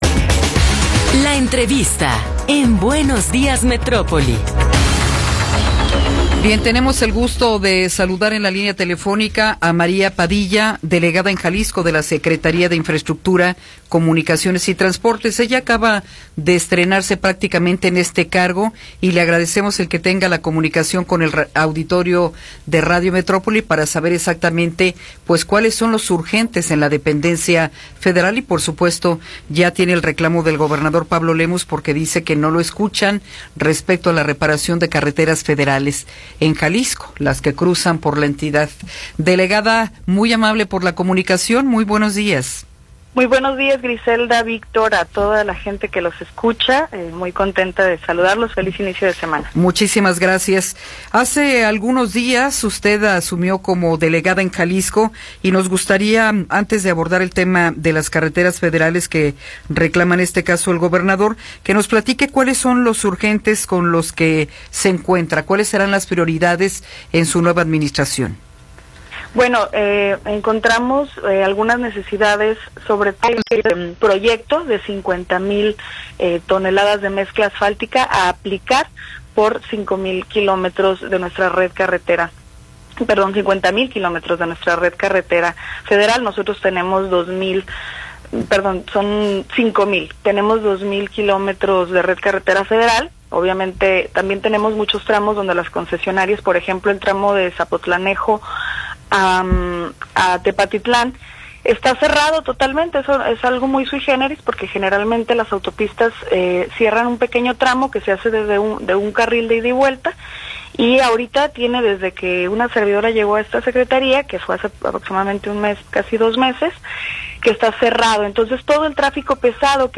Entrevista con María Padilla Romo